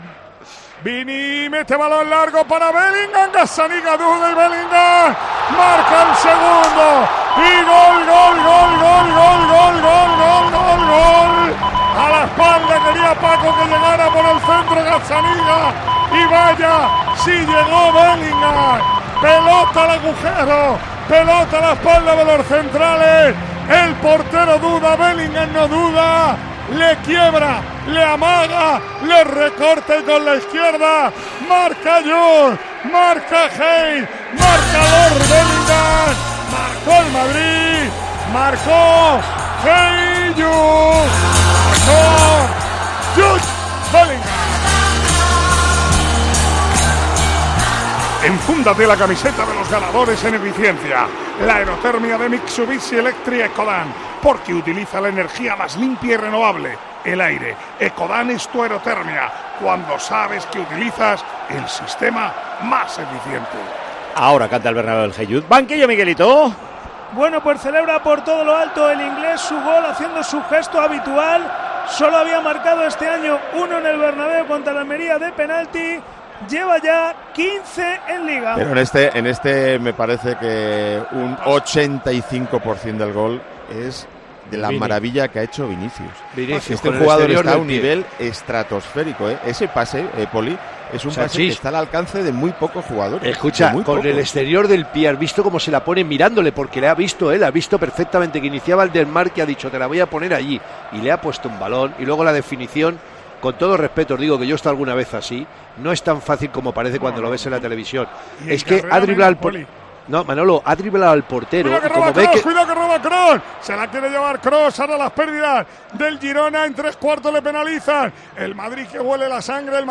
Así vivimos en Tiempo de Juego la retransmisión del Real Madrid - Girona